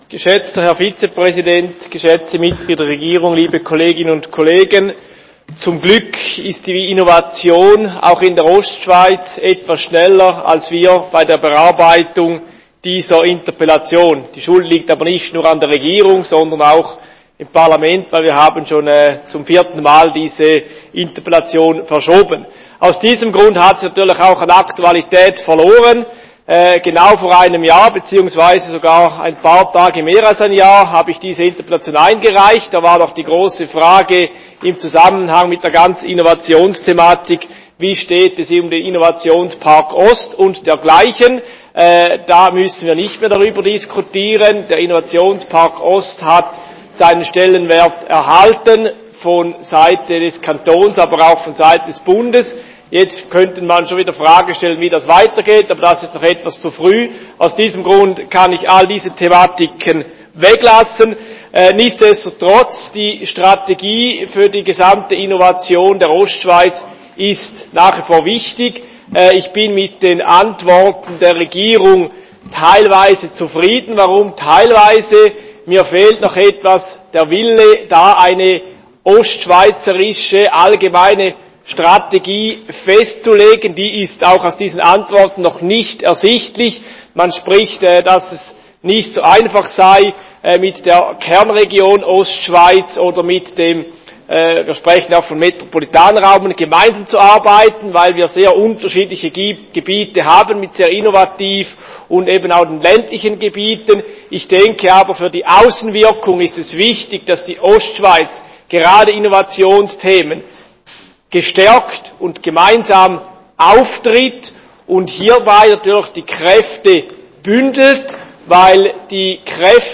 20.9.2021Wortmeldung
Session des Kantonsrates vom 20. bis 22. September 2021